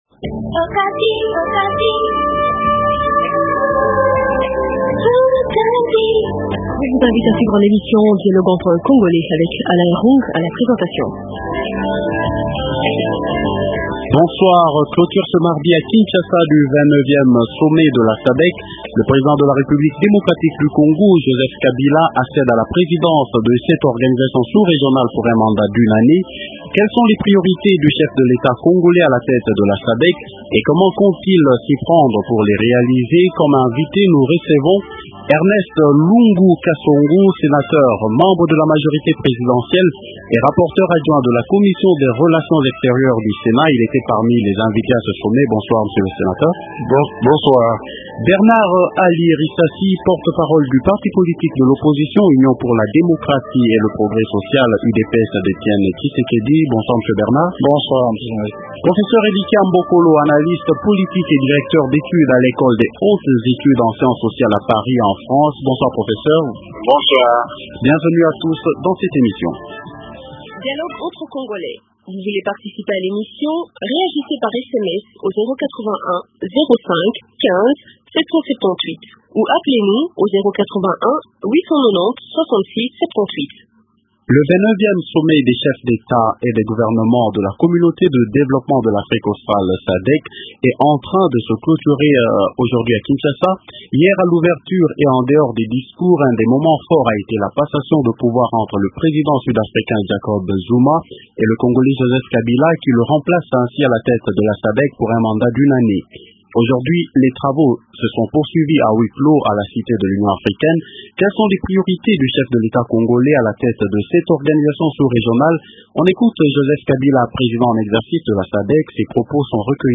- Quelles sont les priorités du chef de l’Etat congolais à la tête de la SADC et comment compte- t- il s’y prendre pour les réaliser ? Invité -Ernest Lungu Kasongo, Sénateur Amp et rapporteur adjoint de la commission des relations extérieures du Sénat.